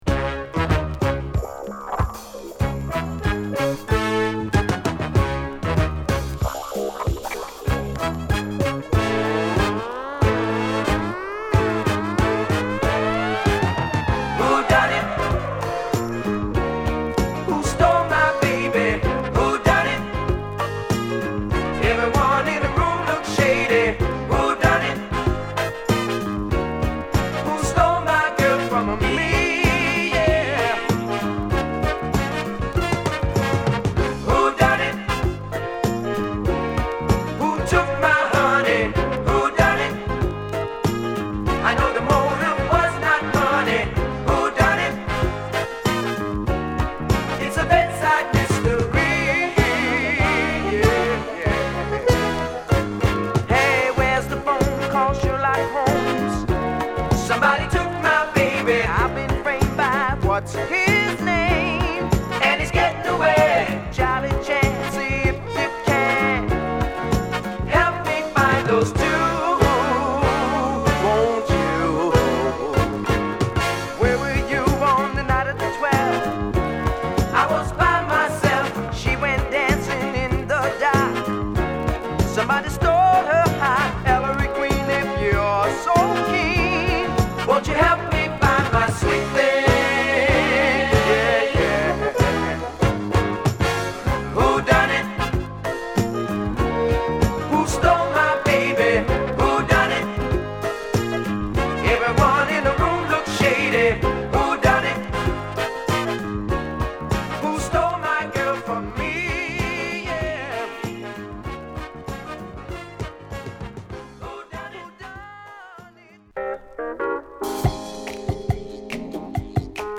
メンバー全員がリードをとる、ヴォーカルグループ